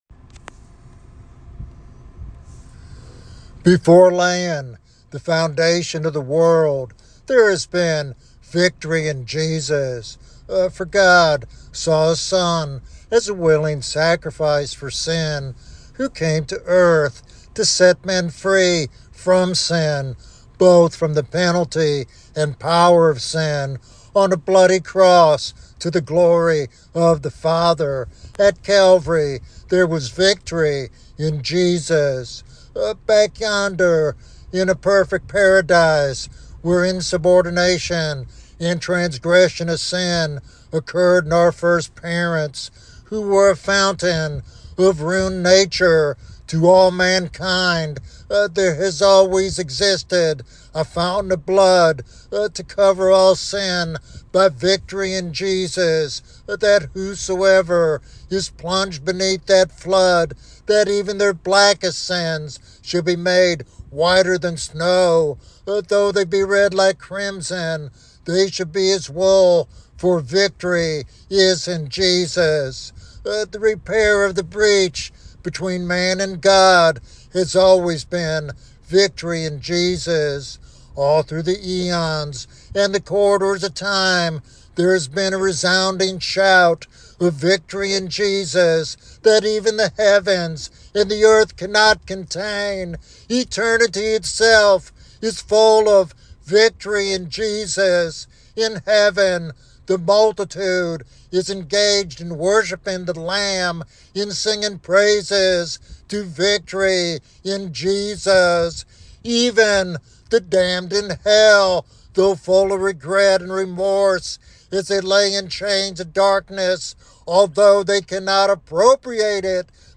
In this powerful devotional sermon